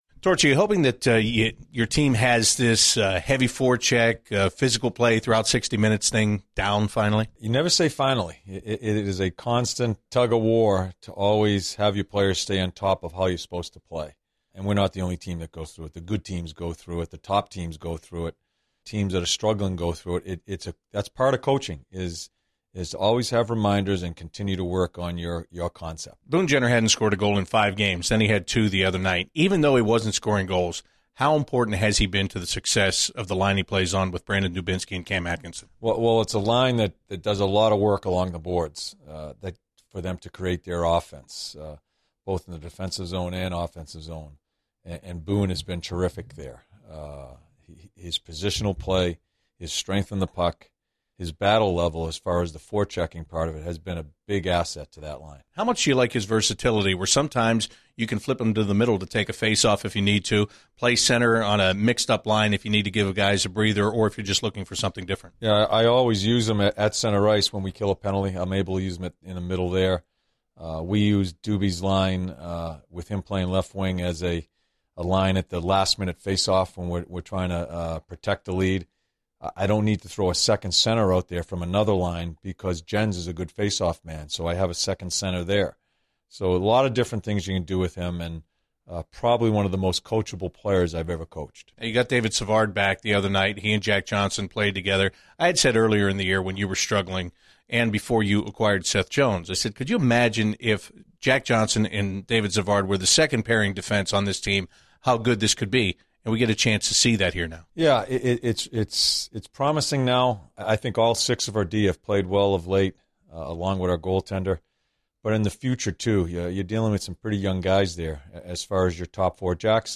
An episode by CBJ Interviews